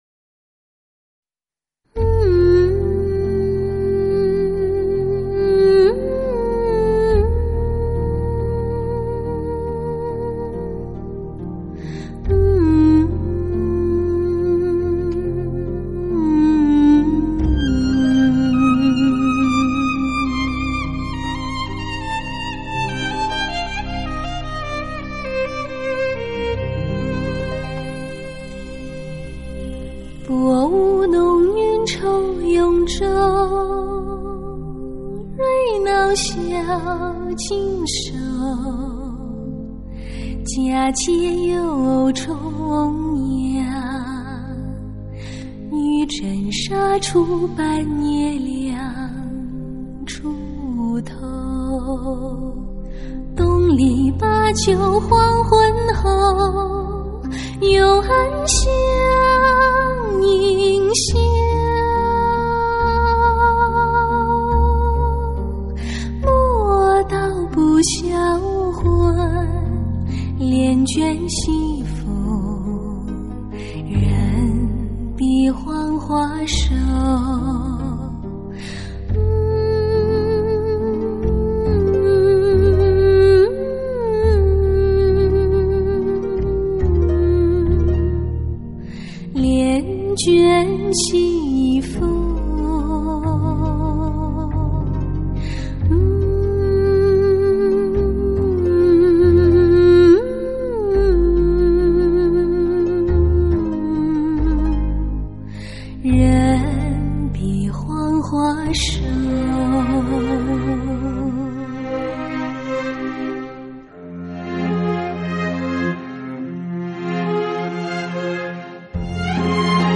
用上一貫甜到漏的聲線徐徐哼唱
今次以DSD混音
音色既甜且美